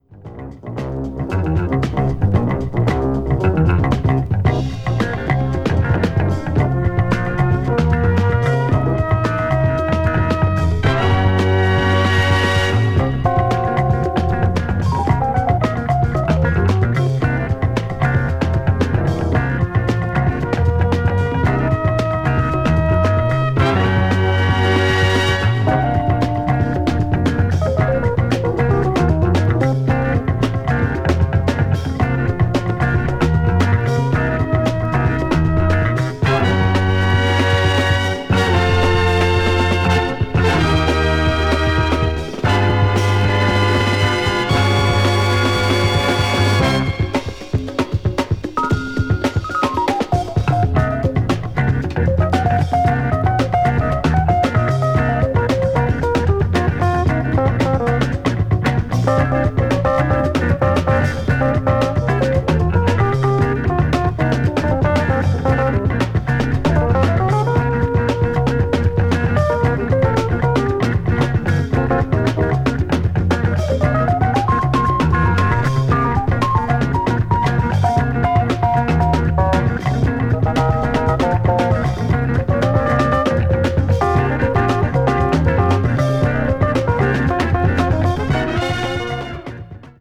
media : EX-/EX-(薄いスリキズによるわずかなチリノイズ/一部軽いチリノイズが入る箇所あり)